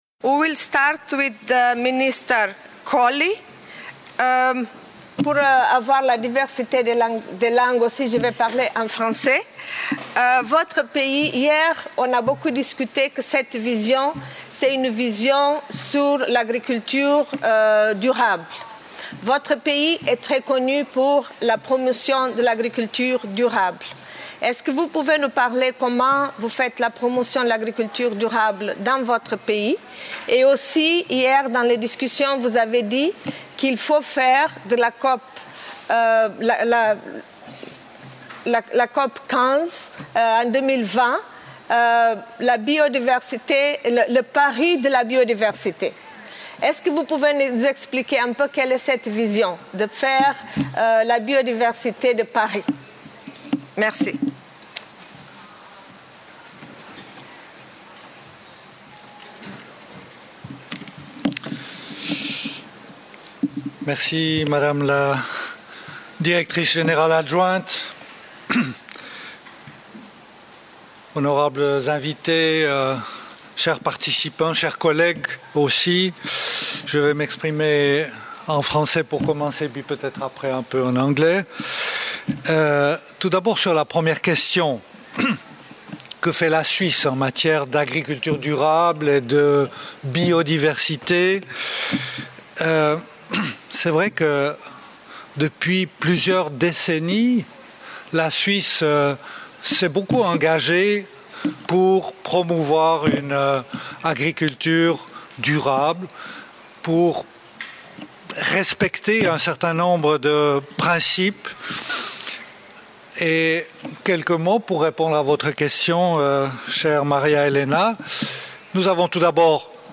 Around 15 Ministers attended the High-Level Segment today, at FAO headquarters, representing both the agricultural sectors and the environmental sector.
Panel Discussion: Mainstreaming Biodiversity at National Level: Policies, Legislation, Incentives, Investment, Value chains